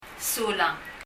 3.　2.よりもっと informal な表現　　Sulang　　[su:ləŋ]
発音